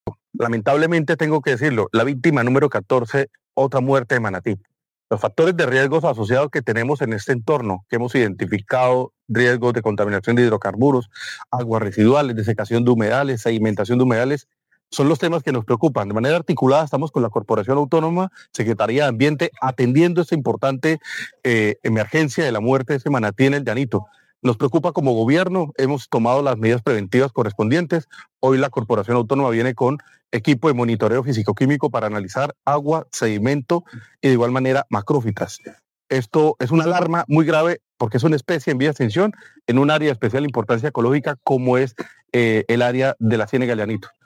Secretario de Ambiente y Transición Energética, Leonardo Granados Cárdenas